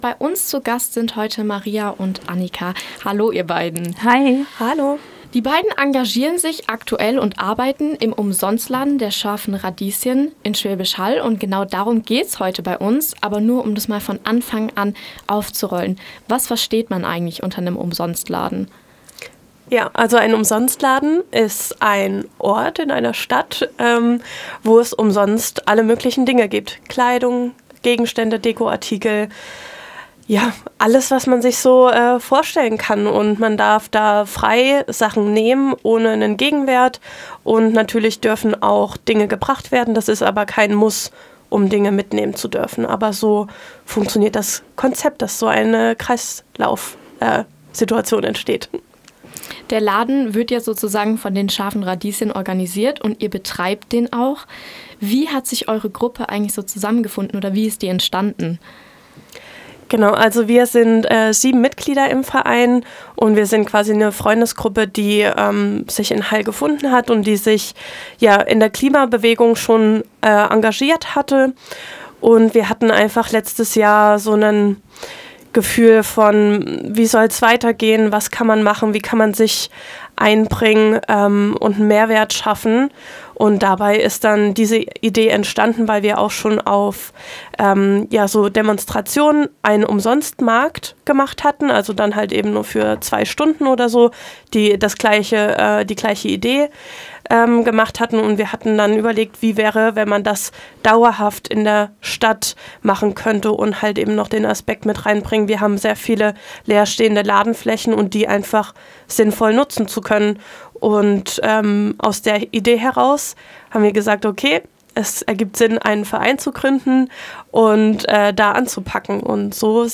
Wie die Idee entstand, was die Besucher erwartet und warum der Laden für Schwäbisch Hall so besonders ist – all das erfahrt ihr in diesem Interview.